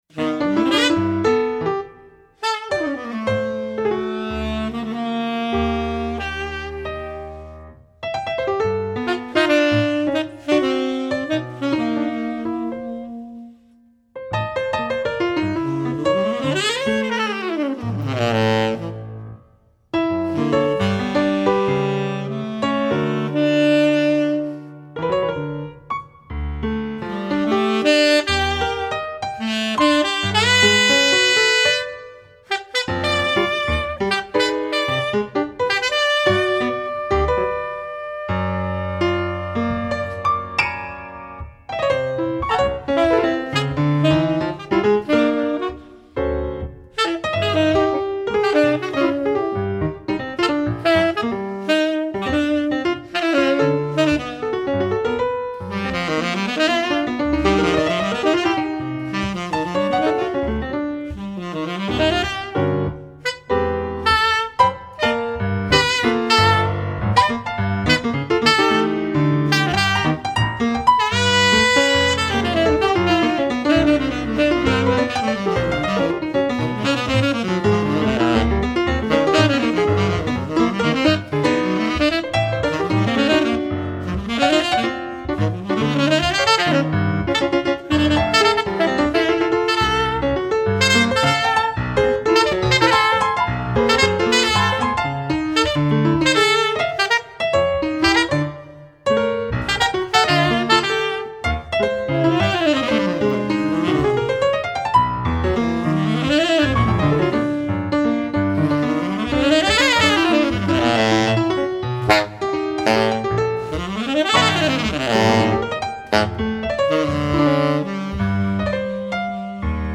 piano, drums
flute, saxophone, shakuhachi
Spontaneous improvisation- no predetermined form.